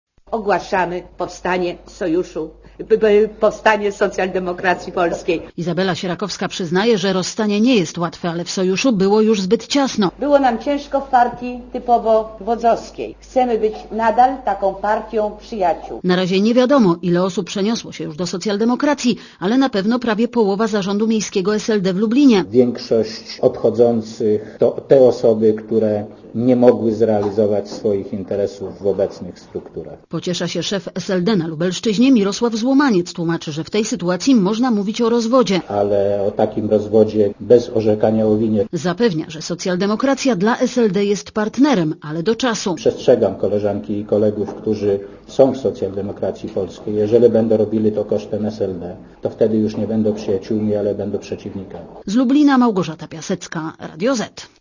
Posłuchaj relacji reporterki Radia Zet (212 KB)